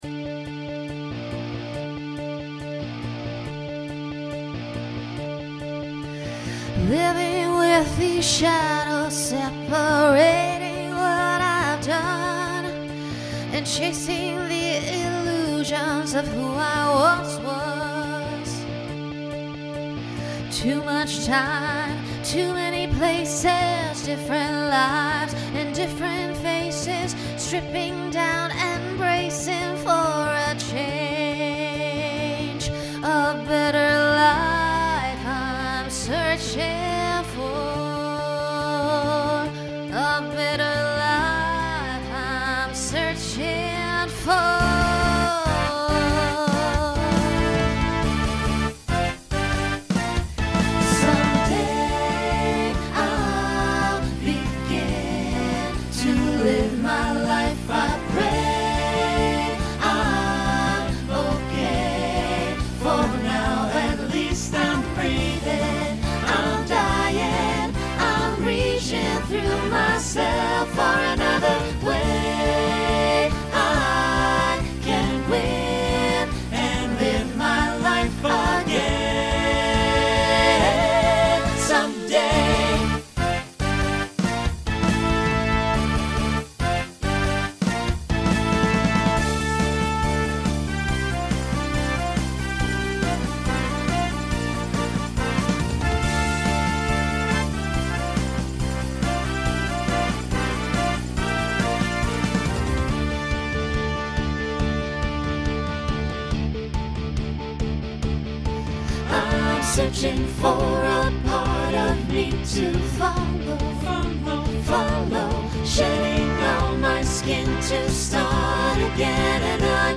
Genre Rock
Opener Voicing SATB